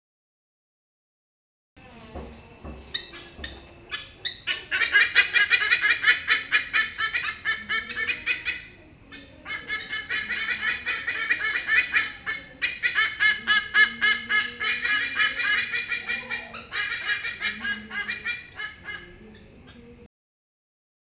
الشروحات العربية أضف شرحاً من سطر واحد لما يُمثِّله هذا الملف الإنجليزية White-bellied Sea Eagle (Haliaeetus leucogaster) flight call from Ezhimala, Kerala
White-bellied_Sea_Eagle_(Haliaeetus_leucogaster)_flight_call_from_Ezhimala,_Kerala.wav